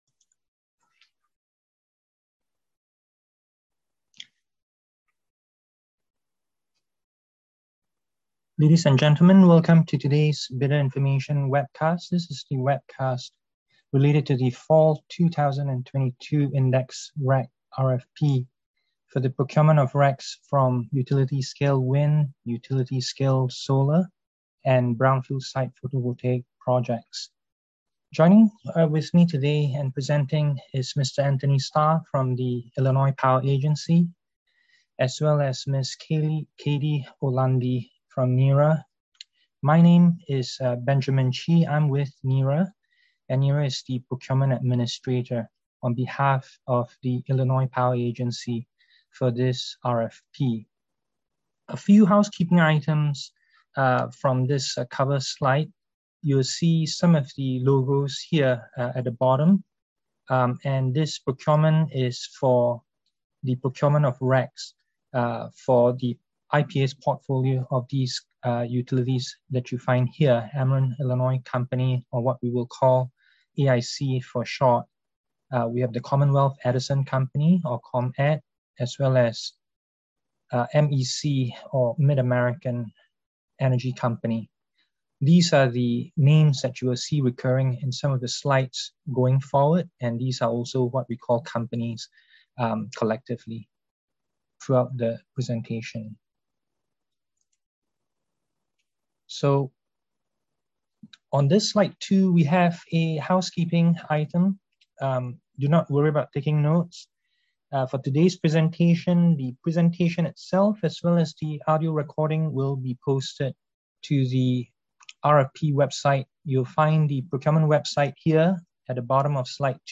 FINAL Fall 2022 Indexed REC RFP Bidder Information Webcast